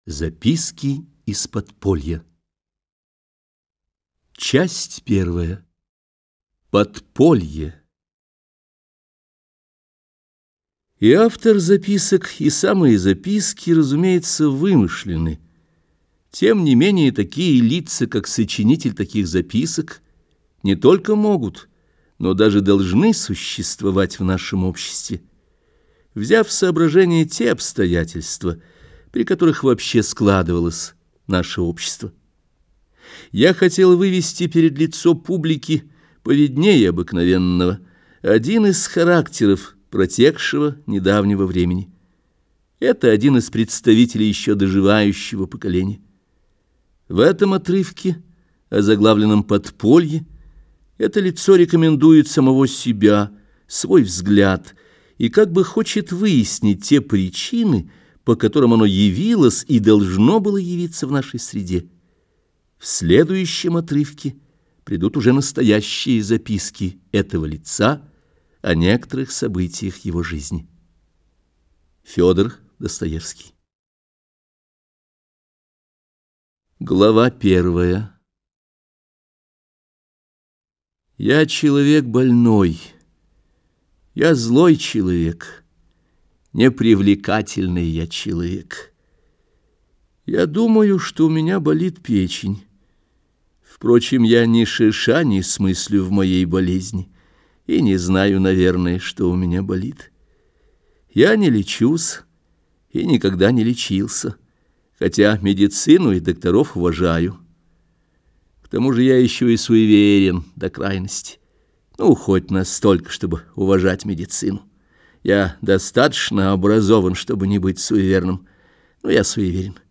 Аудиокнига Игрок. Записки из подполья | Библиотека аудиокниг